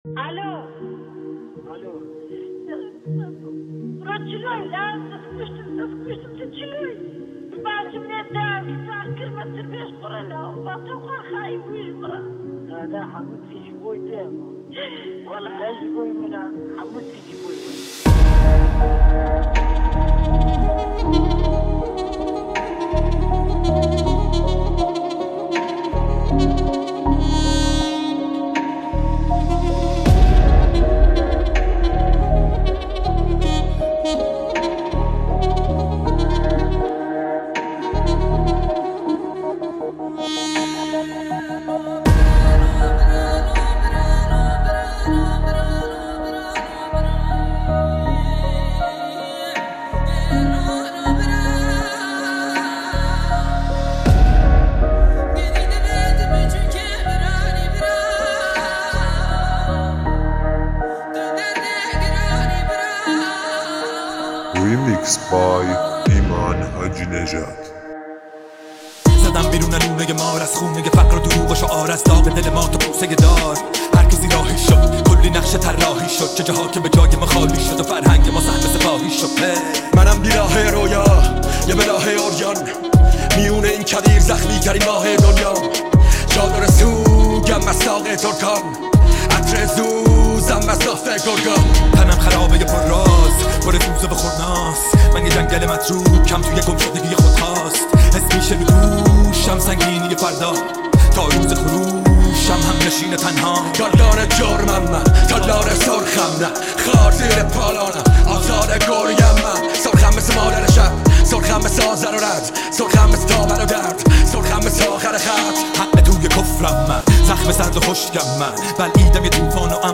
ریمیکس رپی
Remix Rapi